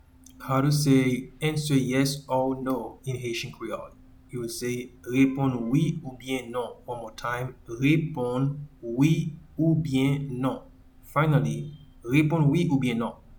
Pronunciation :
Answer-yes-or-no-in-Haitian-Creole-Reponn-wi-oubyen-non.mp3